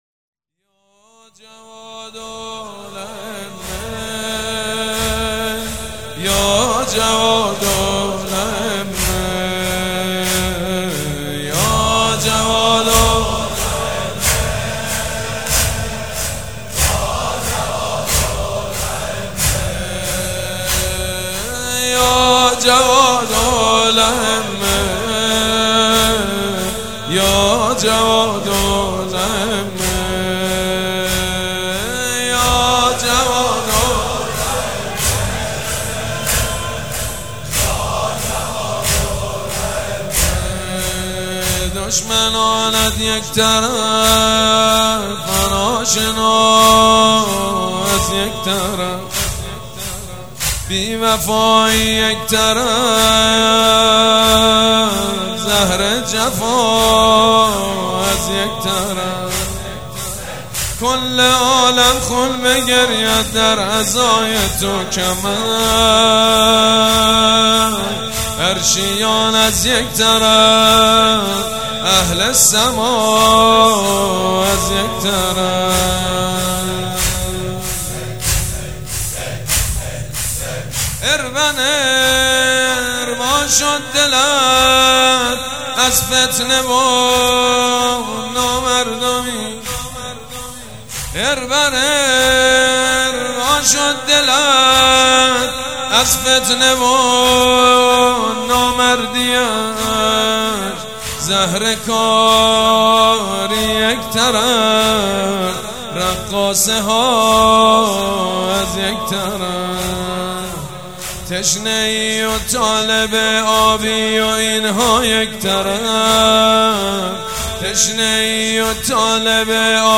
شهادت امام جواد (ع)
زمینه دوم_یا جواد الائمه.mp3